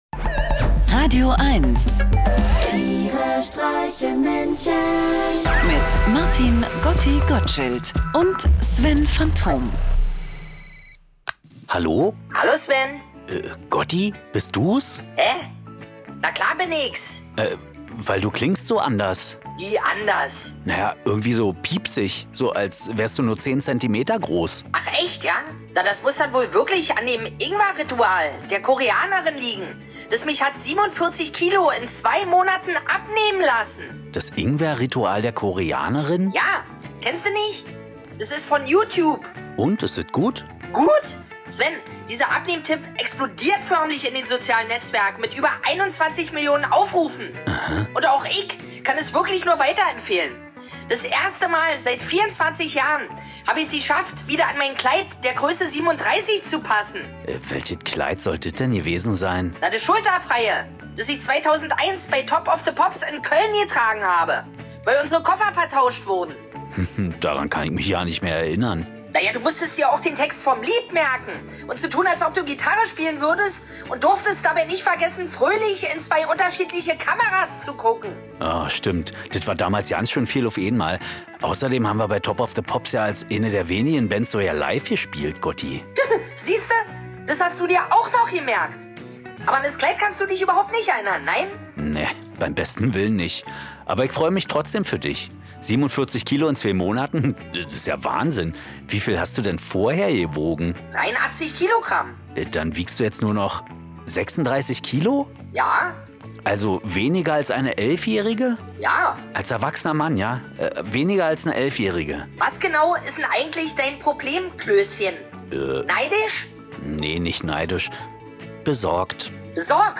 Einer liest, einer singt und dabei entstehen absurde, urkomische, aber auch melancholische Momente.
Comedy